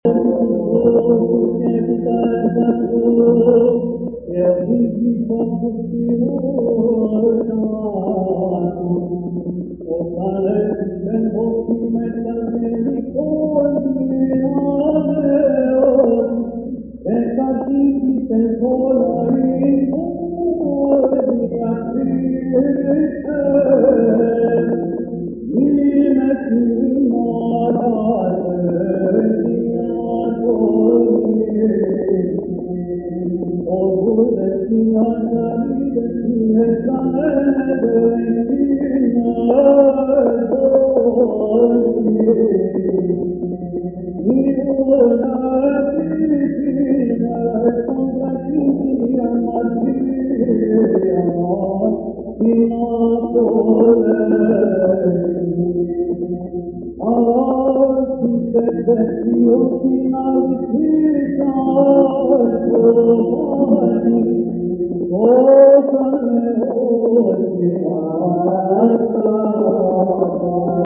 (ἠχογρ. Μεγ. Δευτέρα ἑσπέρας)